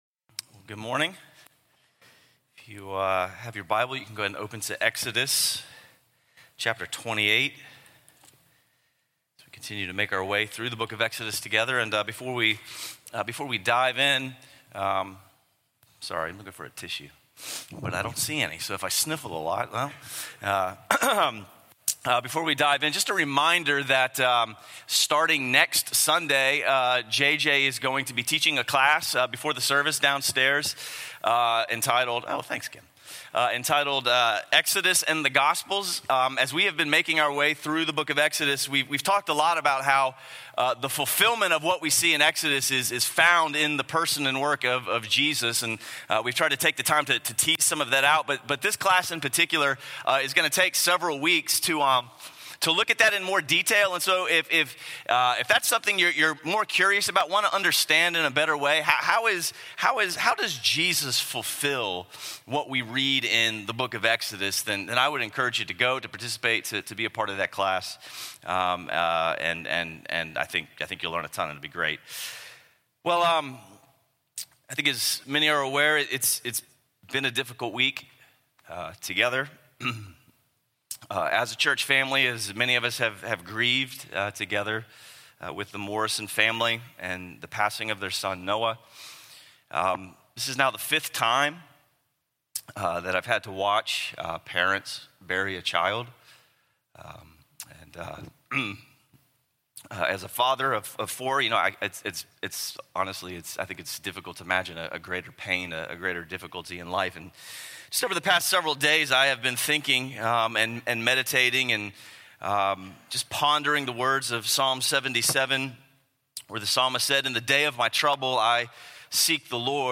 A message from the series "1-1-Six."